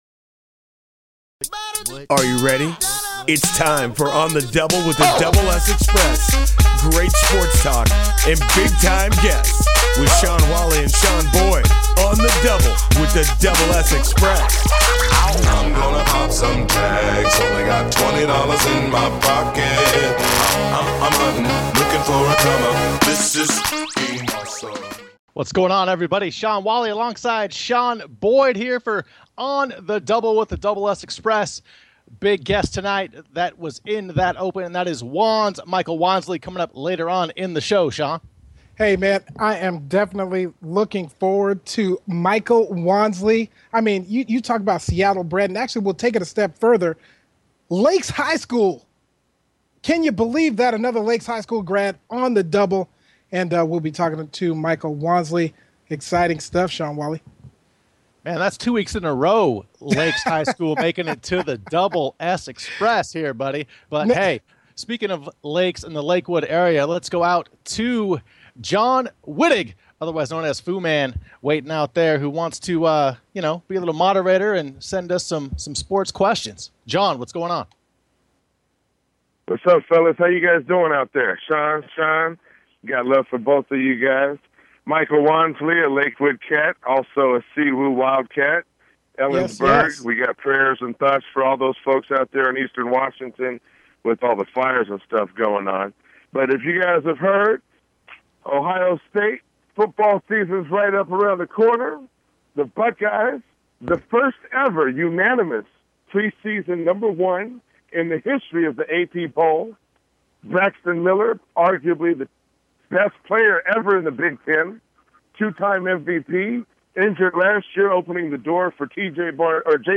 Talk Show Episode
Guest, Wanz